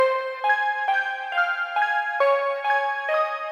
低音总是播放低音。
合成器创建具有不同特征的定义音轨。
Tag: 模拟 EP 电子 生成 合成器 EURORACK 模块化 机的组合物 合成器 噪声